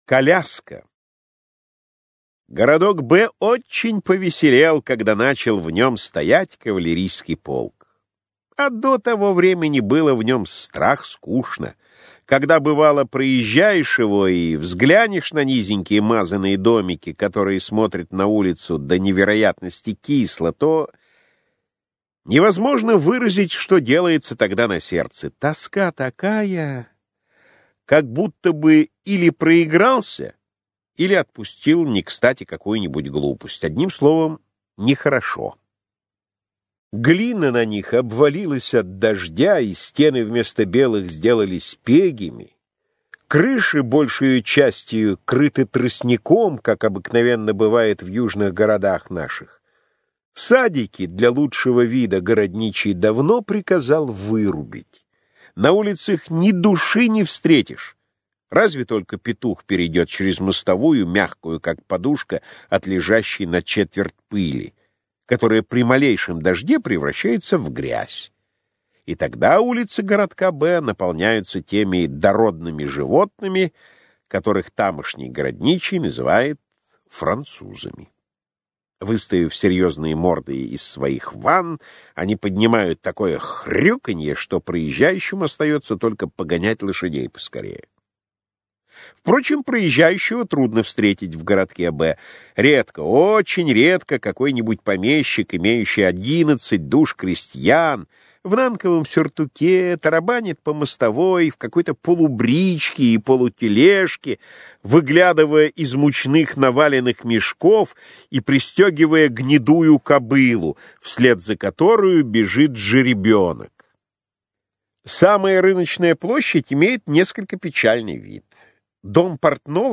Аудиокнига Невский проспект. Петербургские повести.